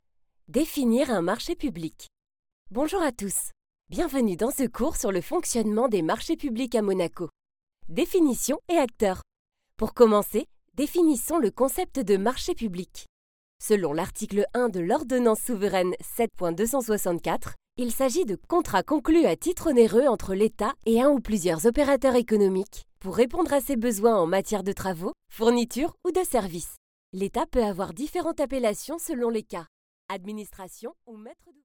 Jeune, Naturelle, Douce, Chaude, Commerciale
E-learning
Avec une voix moyenne, chaude et fluide, je donne vie à vos projets grâce à des narrations authentiques, captivantes et empreintes de sincérité.
Mon studio d’enregistrement professionnel garantit une qualité sonore optimale pour vos productions.